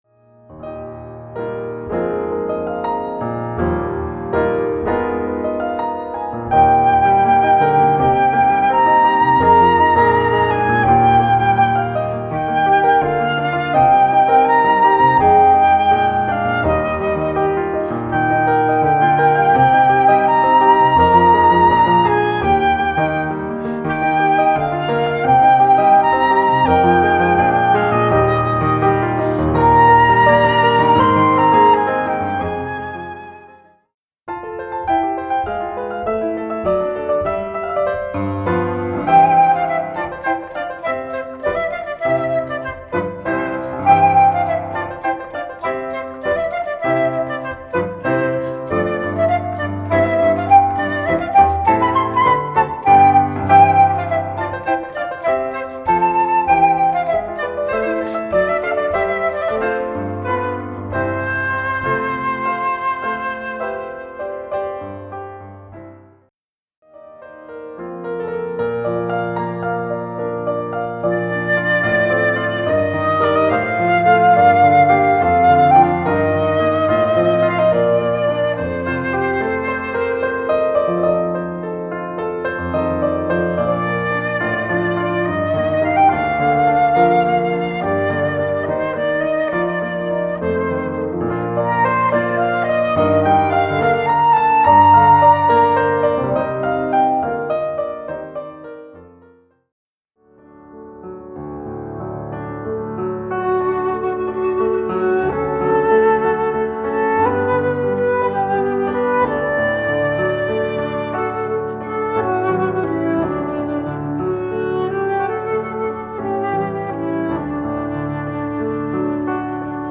Flûte Traversiere et Piano